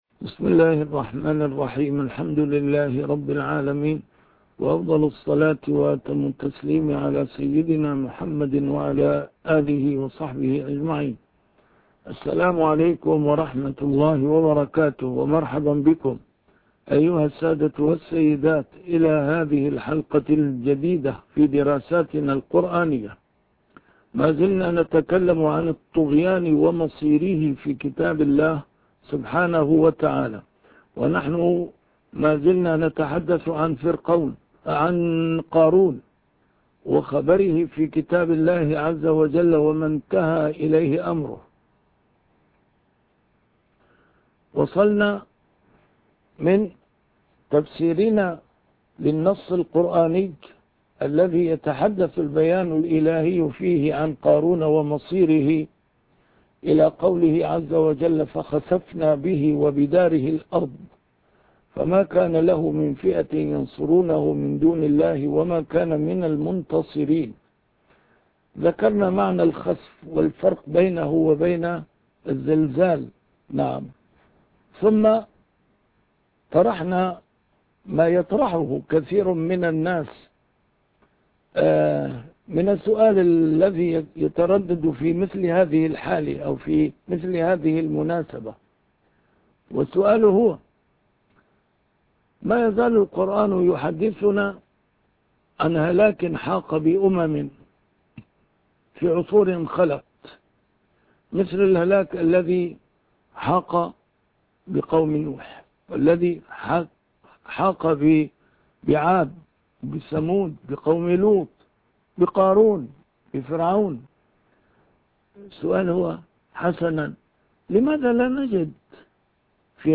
A MARTYR SCHOLAR: IMAM MUHAMMAD SAEED RAMADAN AL-BOUTI - الدروس العلمية - درسات قرآنية الجزء الثاني - 5- الإفساد في القرآن الكريم